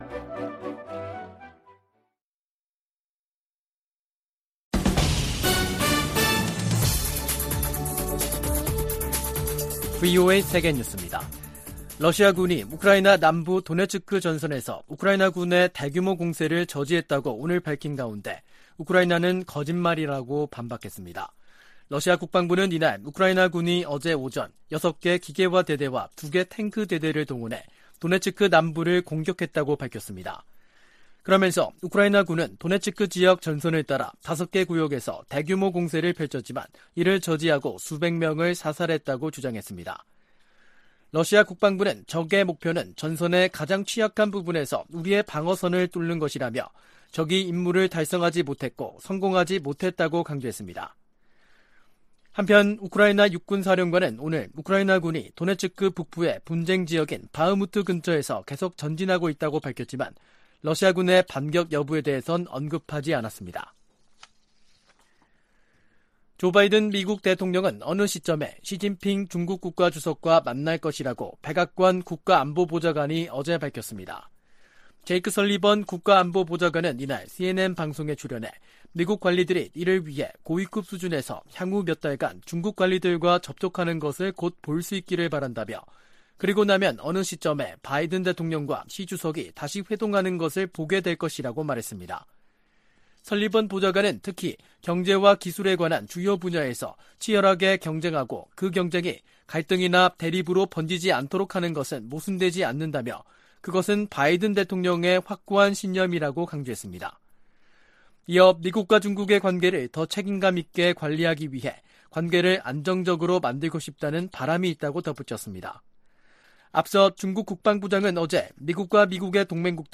VOA 한국어 간판 뉴스 프로그램 '뉴스 투데이', 2023년 6월 5일 3부 방송입니다. 북한 서해위성발사장에서 로켓 장착용 조립 건물이 발사패드 중심부로 이동했습니다. 김여정 북한 노동당 부부장은 유엔 안보리가 군사정찰위성 발사를 단독 안건으로 논의한 데 대해 비난했습니다. 안보리가 북한의 위성 발사에 관한 긴급 공개회의를 개최했지만 공식 대응에는 합의하지 못했습니다.